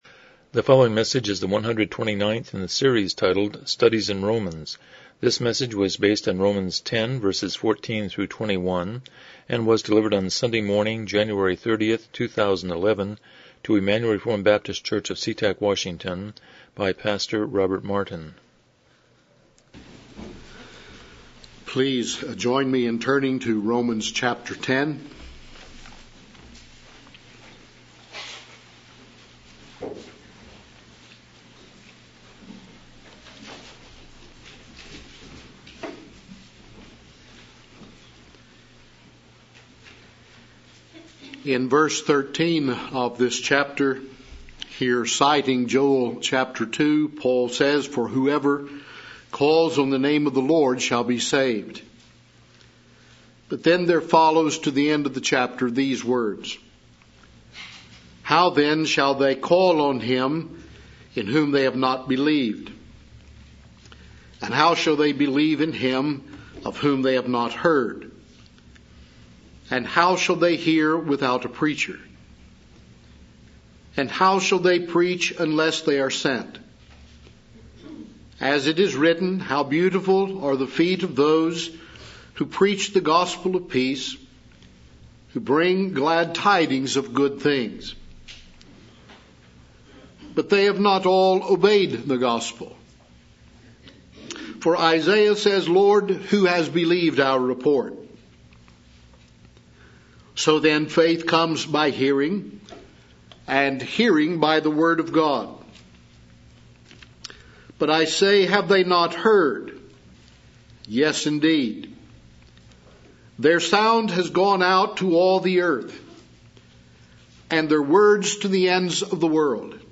Romans 10:14-21 Service Type: Morning Worship « 118 Chapter 22:8 The Sabbath